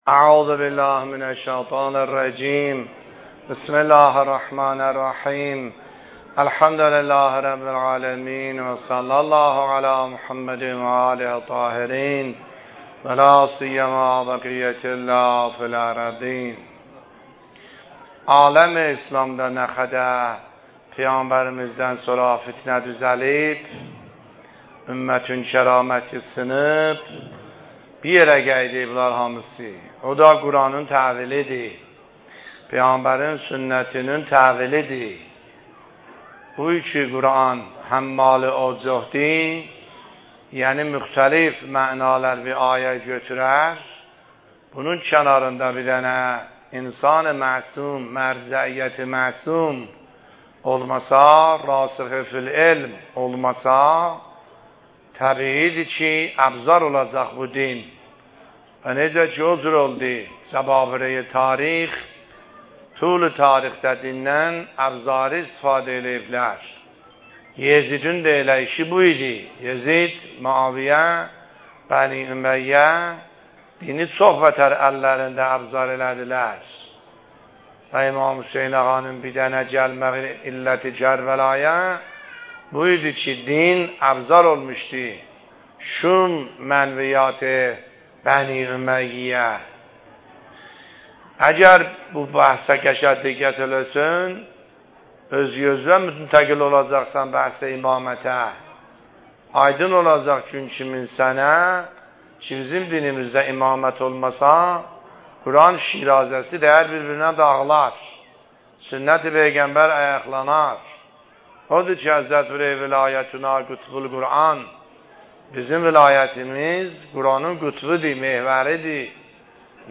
سخنرانی آیه الله سیدحسن عاملی فایل شماره ۸ - دهه اول محرم ۱۳۹۷